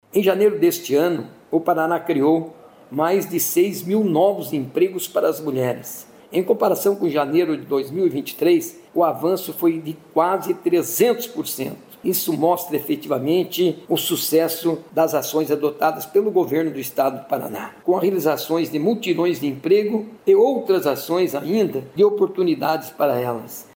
Sonora do secretário do Trabalho, Qualificação e Renda, Mauro Moraes, sobre o Paraná ter o terceiro melhor resultado em empregos para mulheres em janeiro